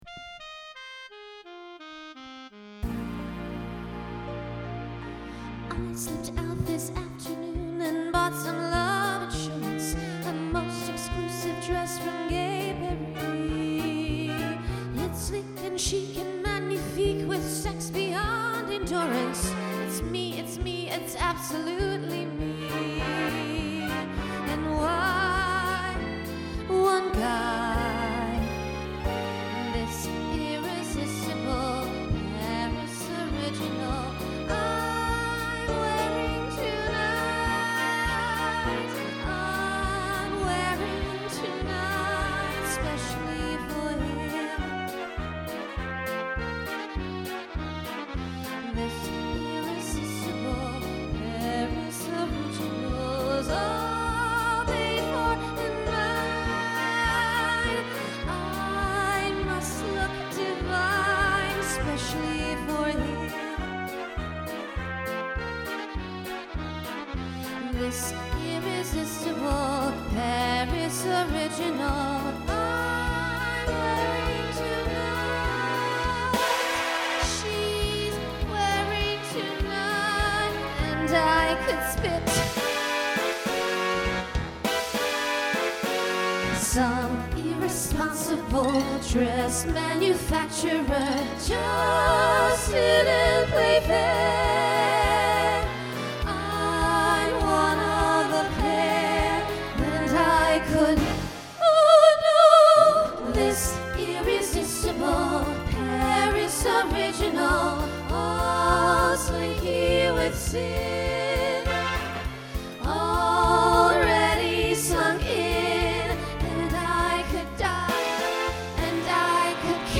Voicing SSA Instrumental combo Genre Broadway/Film
Mid-tempo , Novelty